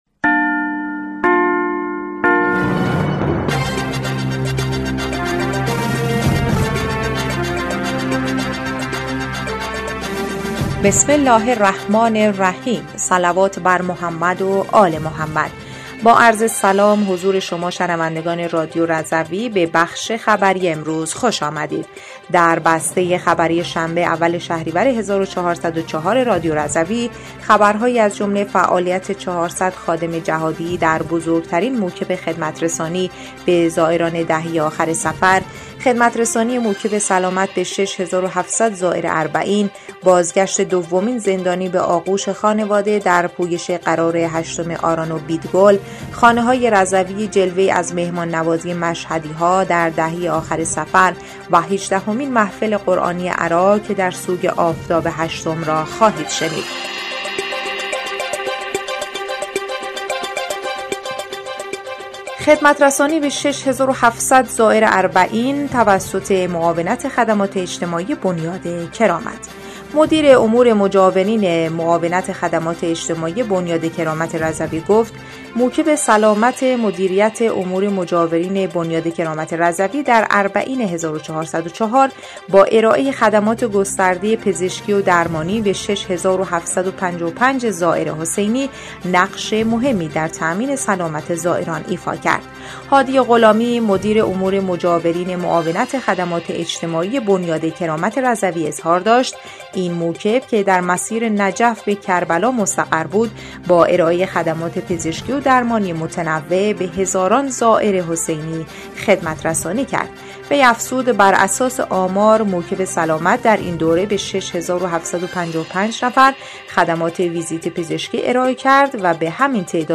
بسته خبری اول شهریور ۱۴۰۴ رادیو رضوی/